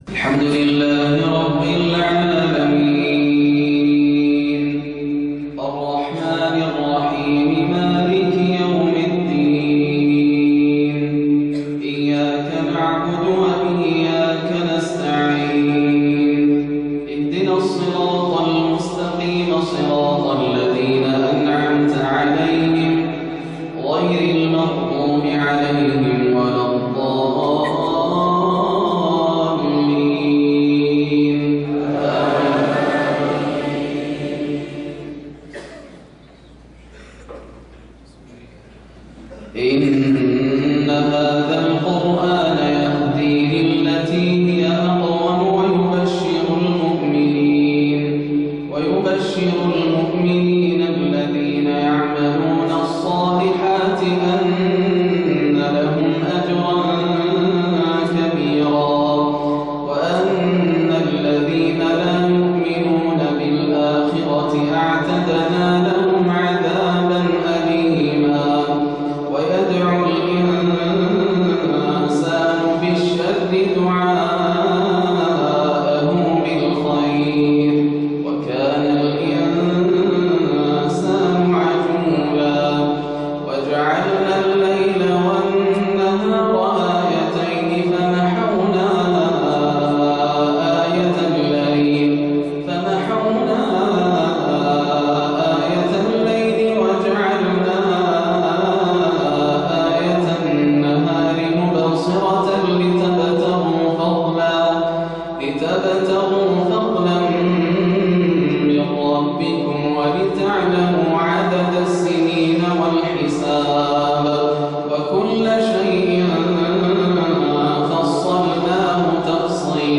صلاة العشاء 7-6-1434هـ من جامع الشيخ محمد بن يحيى الجهيمي > عام 1434 > الفروض - تلاوات ياسر الدوسري